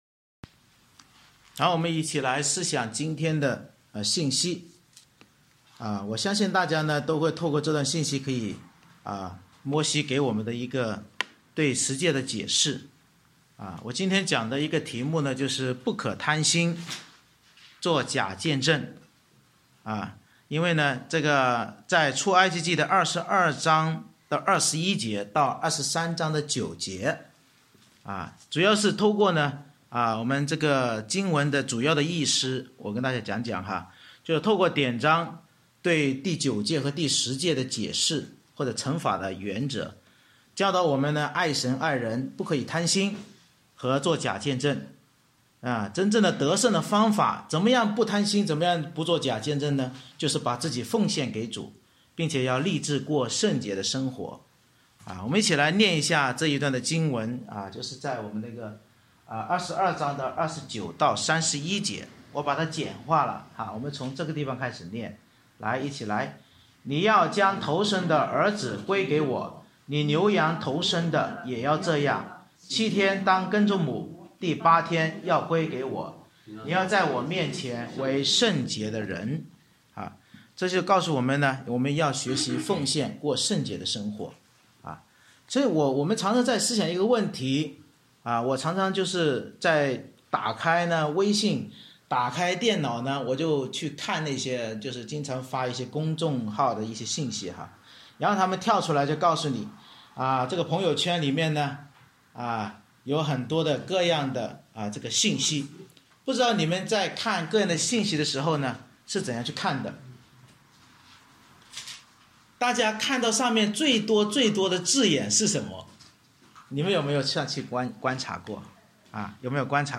出埃及记22:21-23:9 Service Type: 主日崇拜 Bible Text